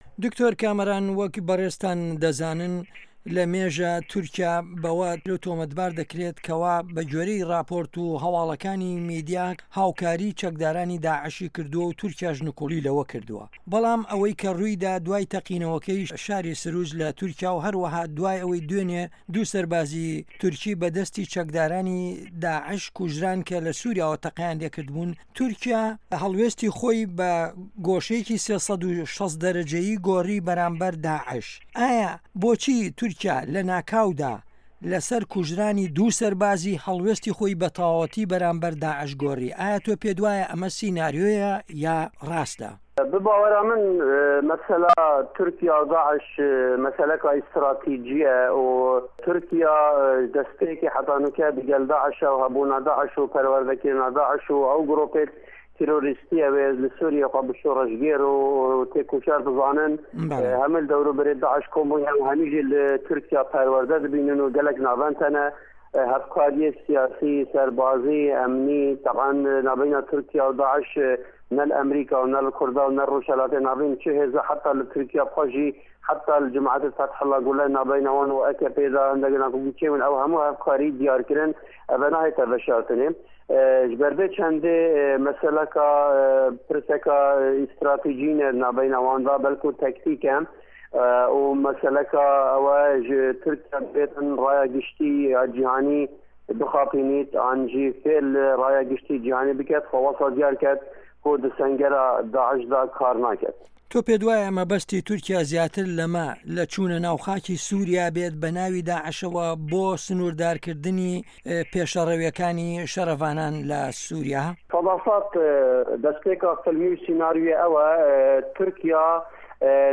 وتوێژ